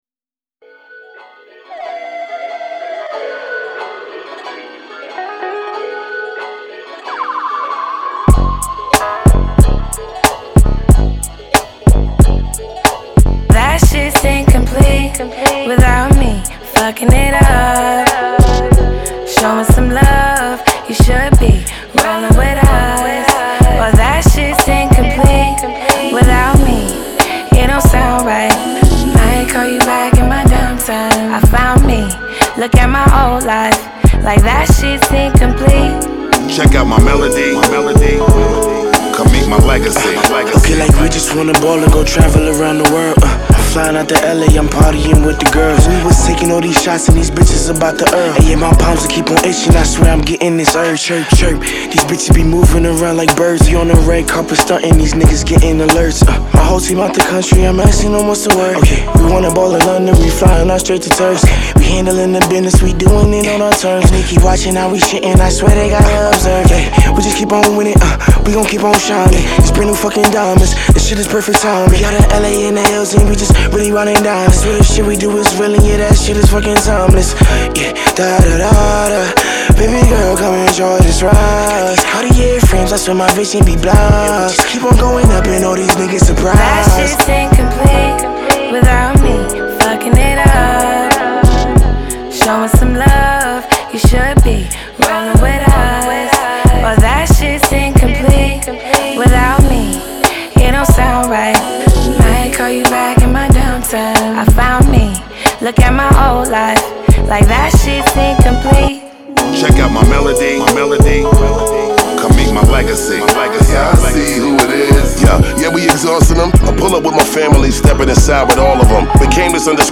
Genre : Hip-Hop, Rap